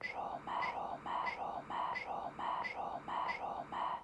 I used my iPhone for these recordings and covered it in some cases to produce a muffled sound.
I then recorded some ambient sounds and whispers of comments made in the interviews that I had seen.
It intrigues me the different sounds that use the repetition of words/phrases, so I firstly recorded a couple of tracks where the repetition is the same phrase used in the audio track.
repeat-trauma.wav